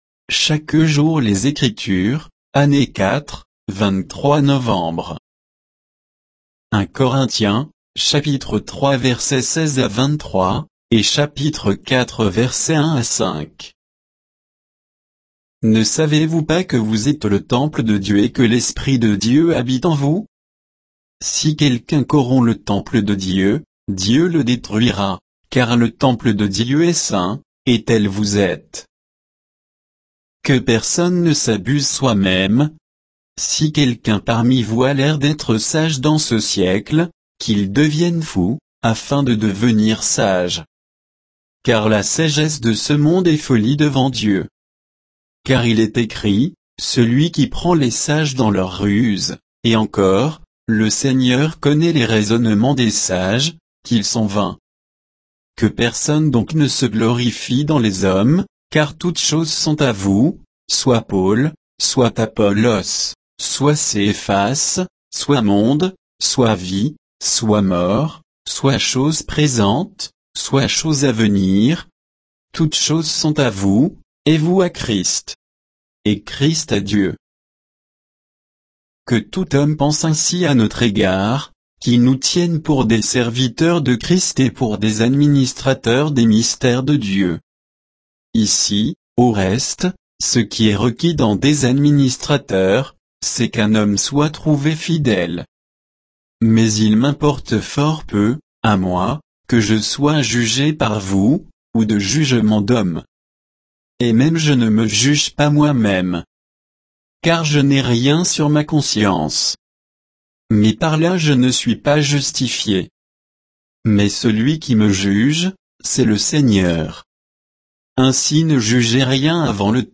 Méditation quoditienne de Chaque jour les Écritures sur 1 Corinthiens 3